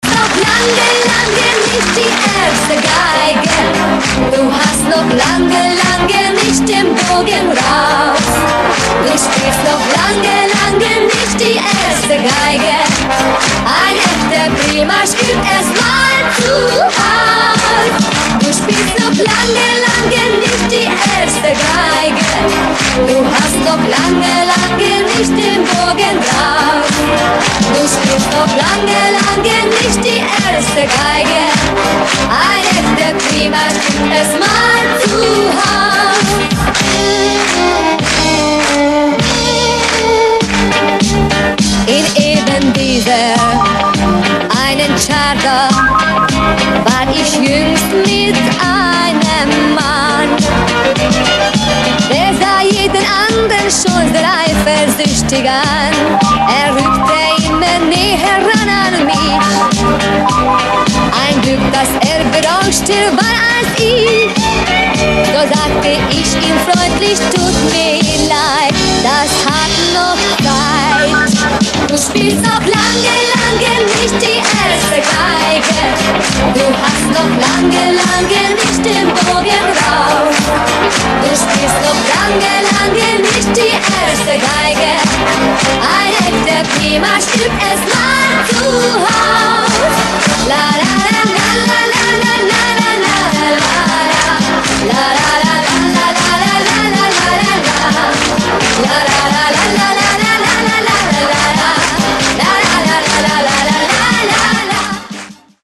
венгерской певицы